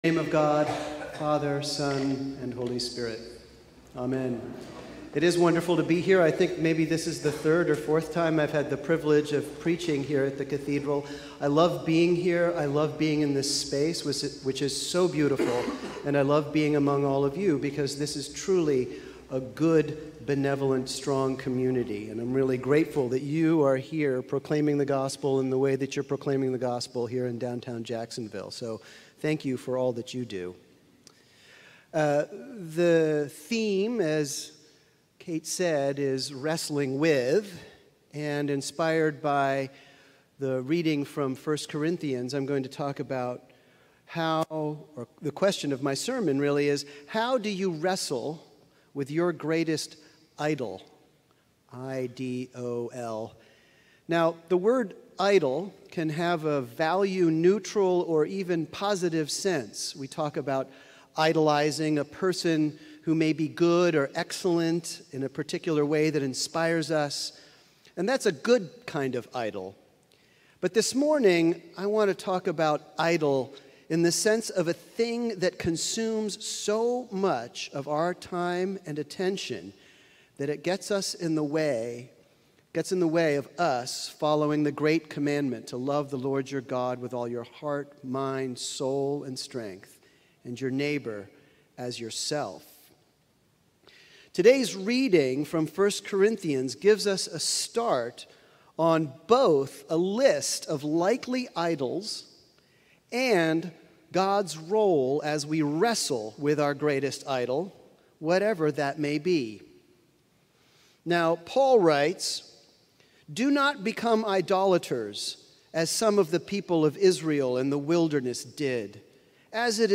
Sermon: Wrestling with Your Greatest Idols